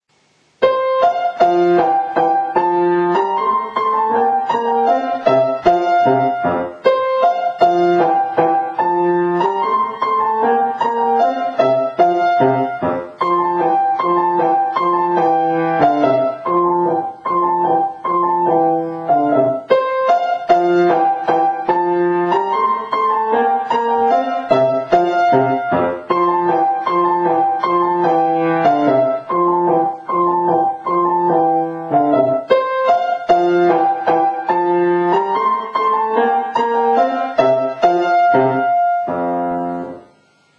ピアノのしらべ
しかも、天真爛漫な明るい音楽で、既にモーツァルトらしい作風に仕上がっています。